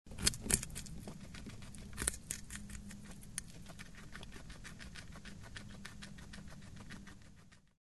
Звук морской свинки которая грызет и кушает